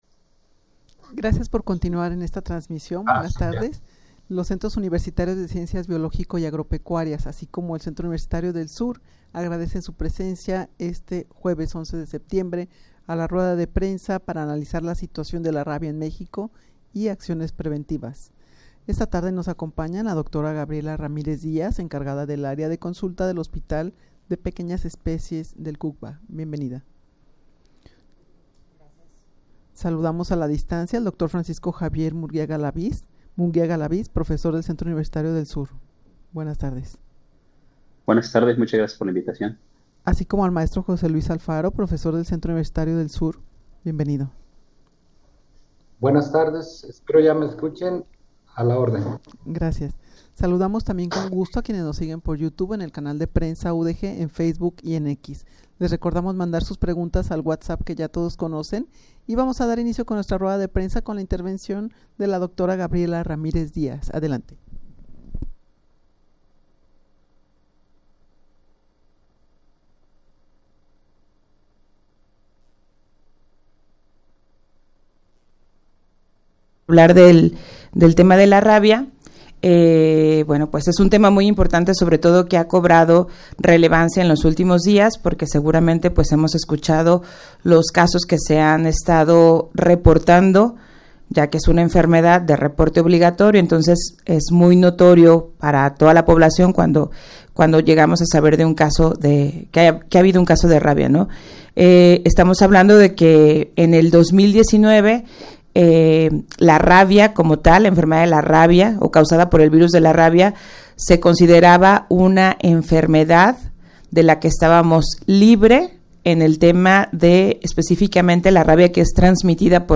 Audio de la Rueda de Prensa
rueda-de-prensa-para-analizar-la-situacion-de-la-rabia-en-mexico-acciones-preventivas.mp3